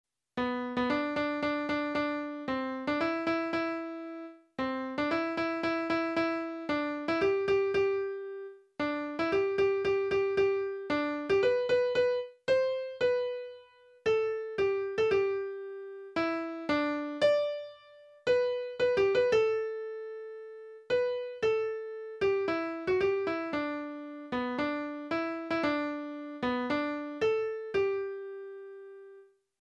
黒神（くろかみ）小学校の校歌